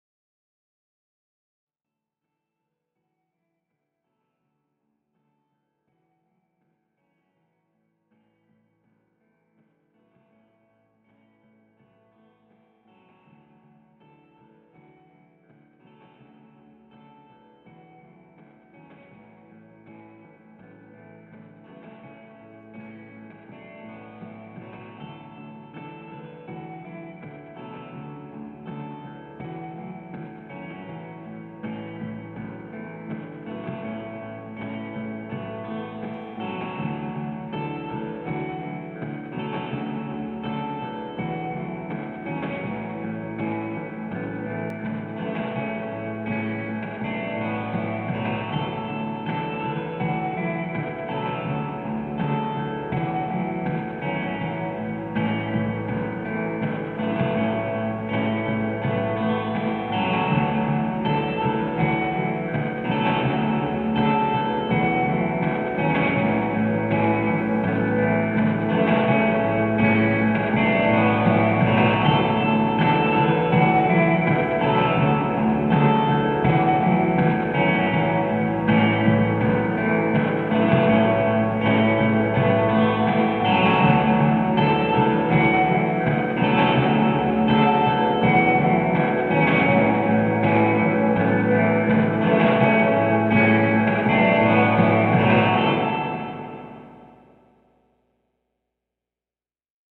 Light, airy, slightly depressing - all the good stuff.